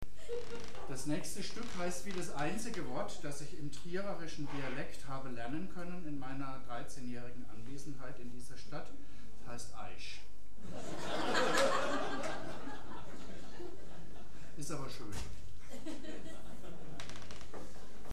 8 07 Ansage [0:18]
07 - Ansage.mp3